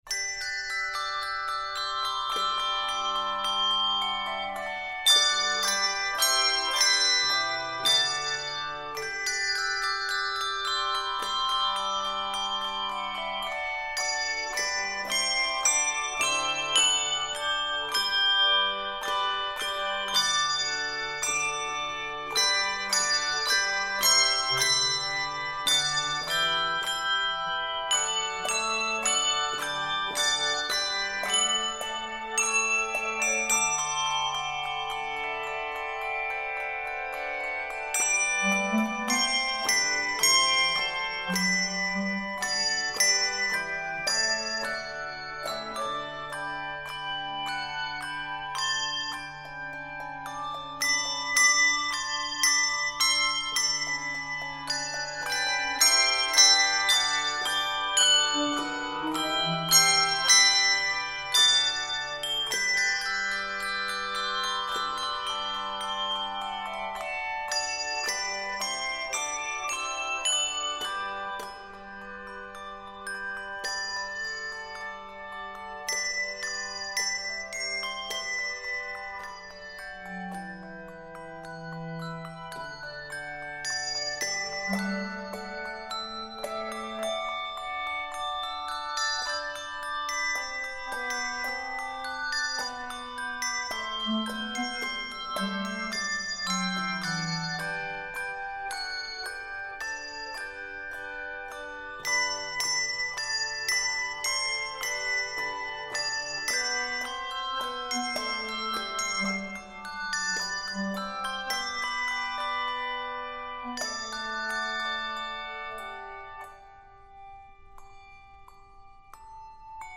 Inc. Your source for handbells